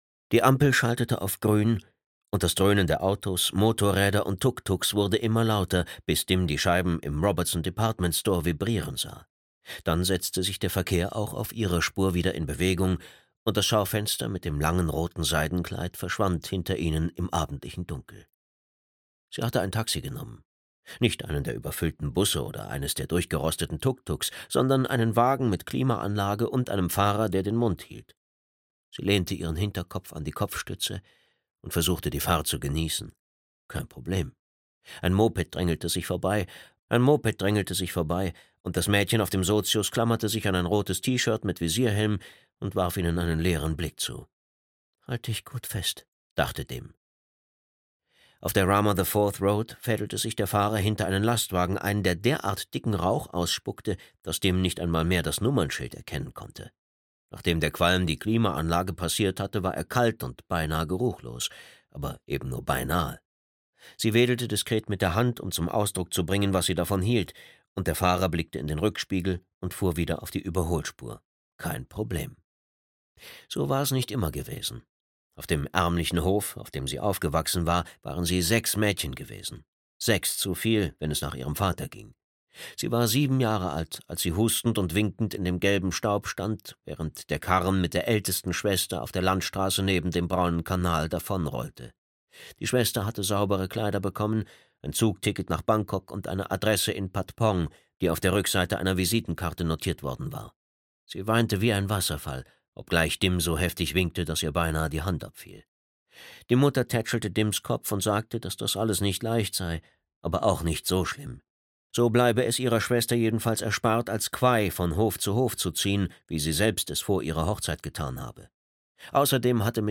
Kakerlaken (DE) audiokniha
Ukázka z knihy